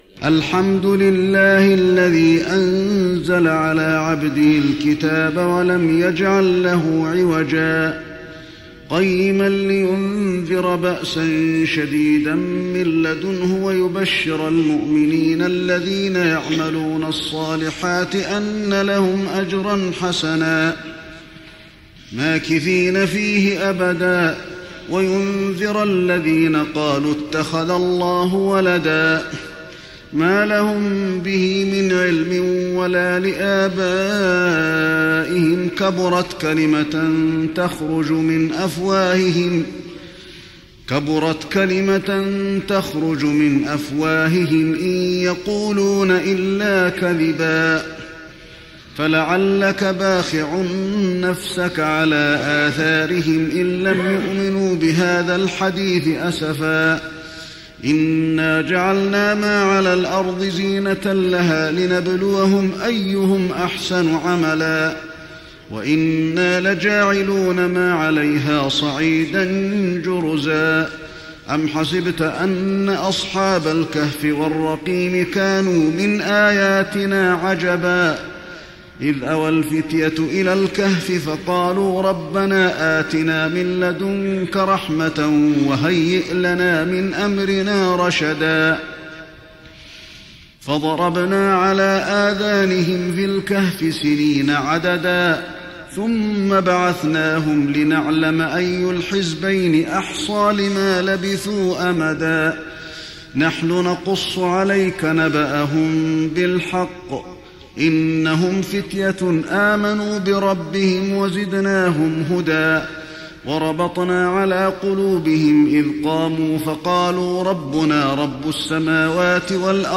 تراويح رمضان 1415هـ سورة الكهف Taraweeh Ramadan 1415H from Surah Al-Kahf > تراويح الحرم النبوي عام 1415 🕌 > التراويح - تلاوات الحرمين